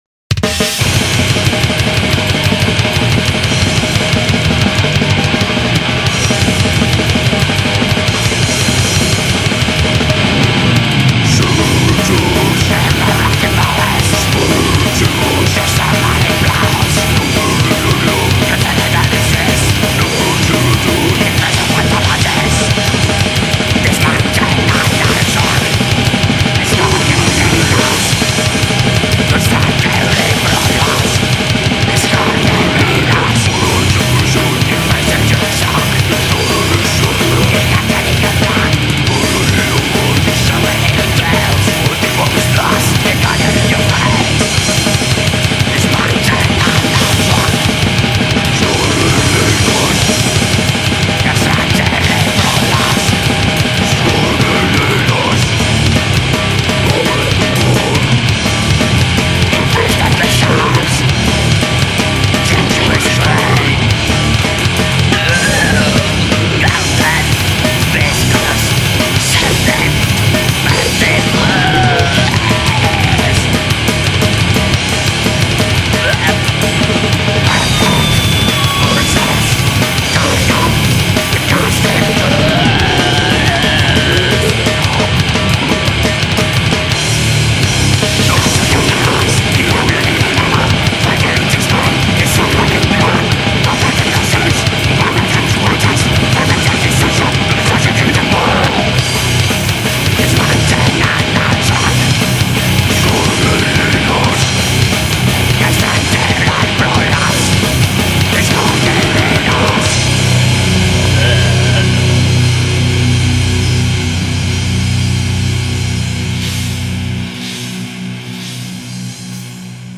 Classical extreme compilation